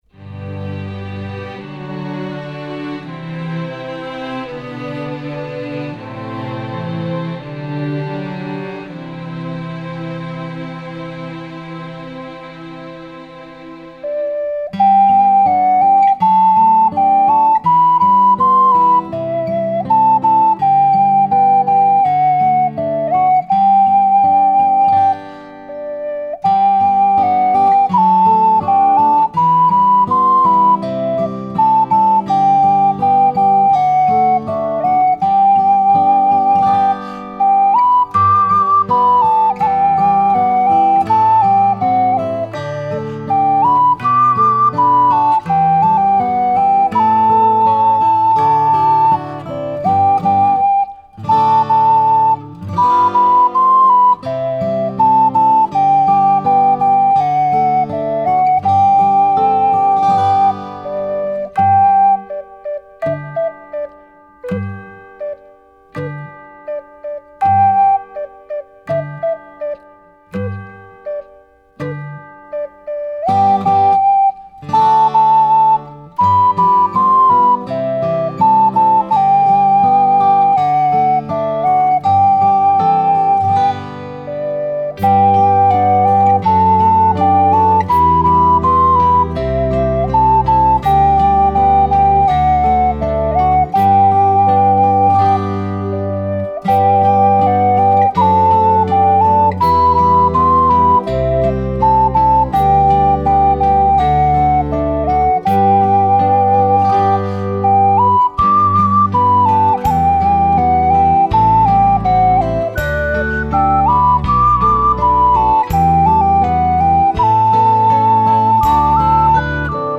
专辑流派： 纯音乐